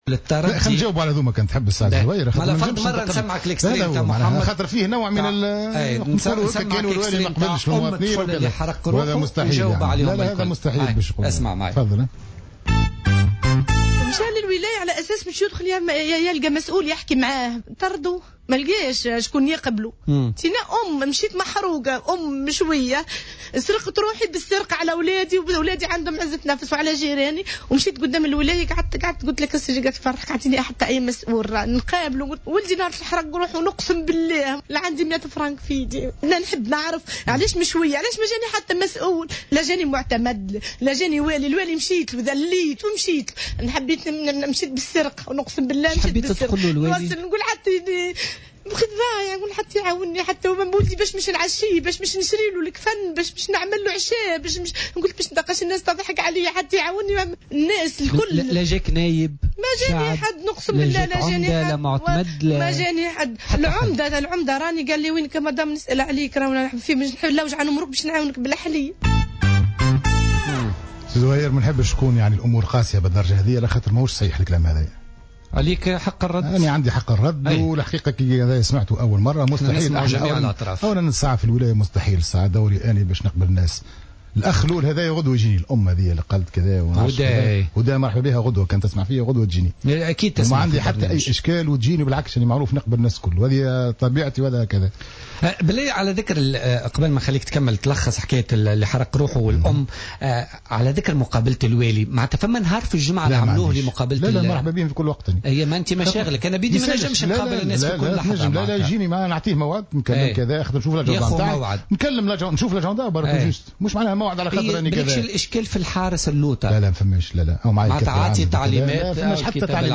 نفى والي سوسة، فتحي بديرة ضيف برنامج بوليتيكا اليوم، الثلاثاء التصريحات التي...